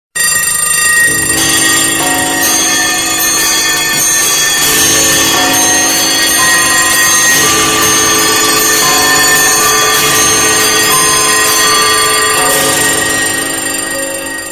زنگ موبایل هشدار